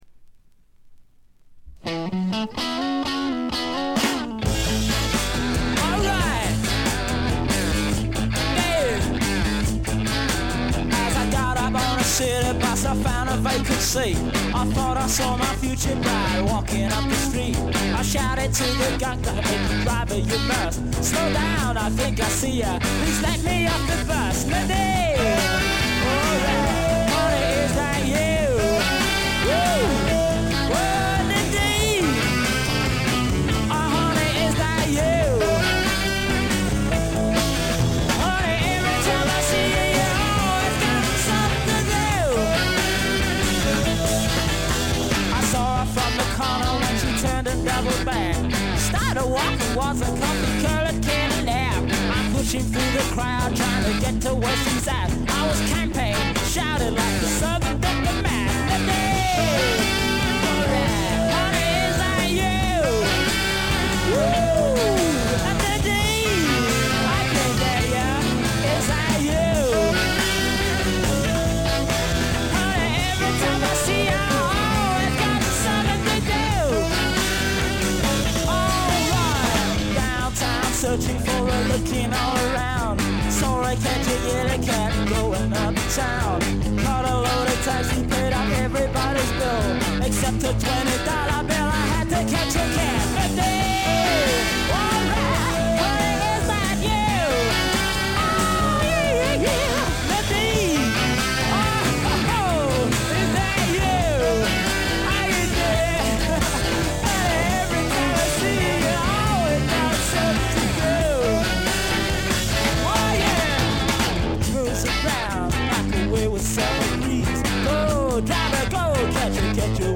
へヴィー・ブルース、ハード・スワンプな名作を残しました。
試聴曲は現品からの取り込み音源です。
lead vocals
steel guitar, backing vocals, mandolin, marimba
Recorded at I.B.C. Studios, London, September 1969